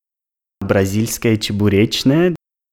prononciation Brazilskaya cheburechnaya ↘ explication Donc ici, à coté de moi, au niveau du rond point de la rue de Bosnie, il y a un café brésilien, où ils font la pâtisserie comme on fait en Russie.